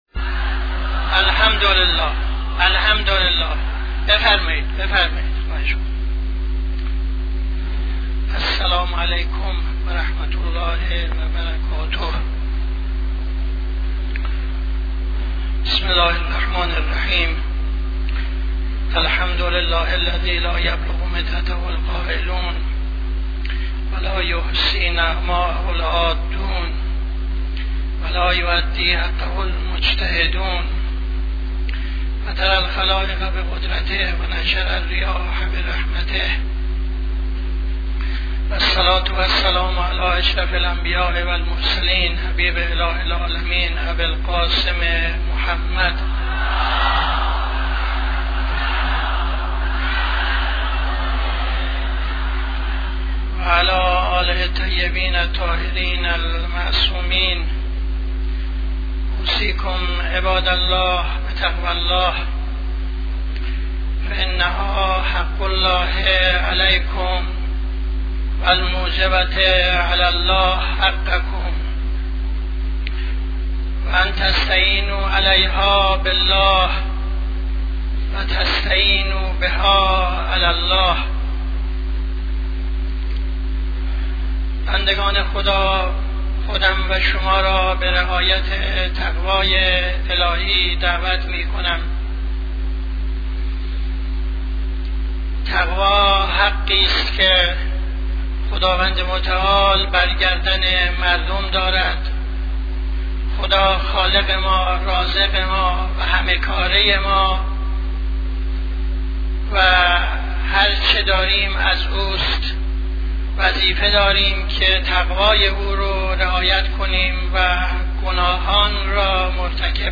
خطبه اول نماز جمعه 07-06-76